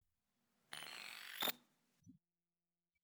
InfoComputerShutdown.wav